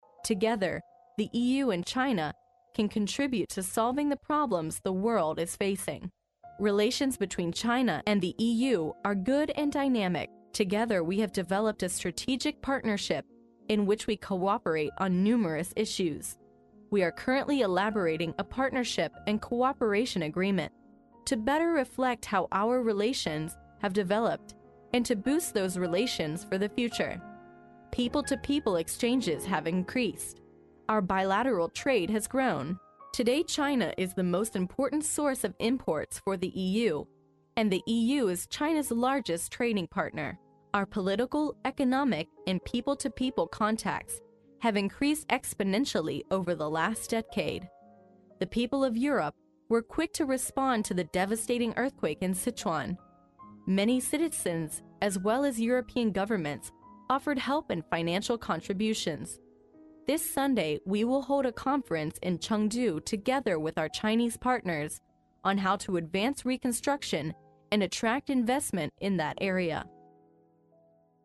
历史英雄名人演讲 第47期:欧盟委员会主席巴罗佐在国家行政学院的演讲(3) 听力文件下载—在线英语听力室